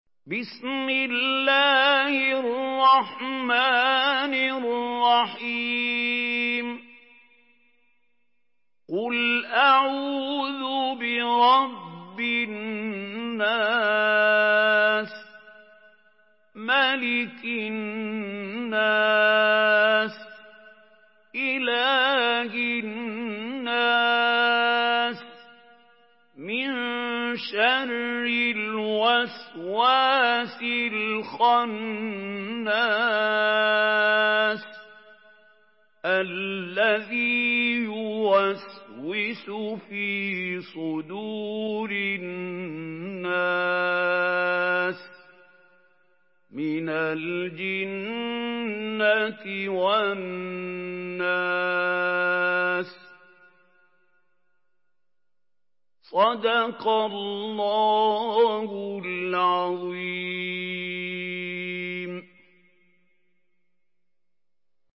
Surah আন-নাস MP3 by Mahmoud Khalil Al-Hussary in Hafs An Asim narration.
Murattal Hafs An Asim